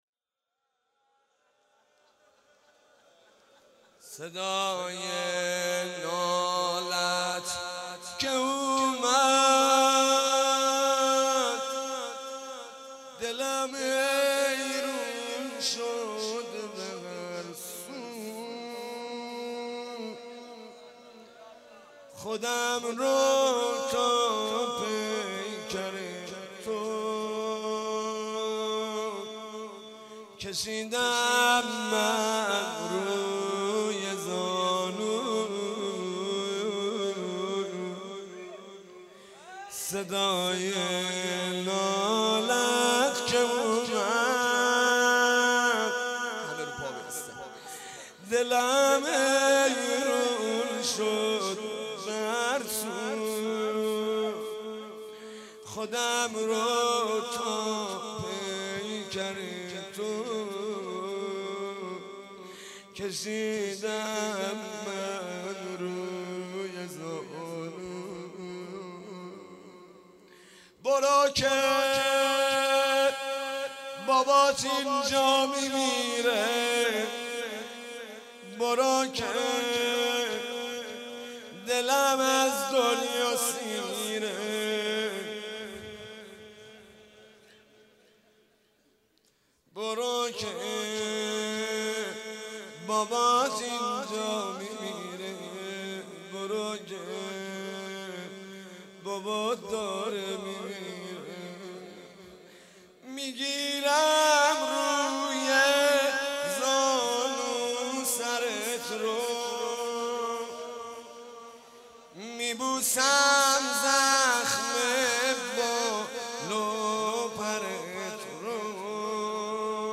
مناسبت : شب هشتم محرم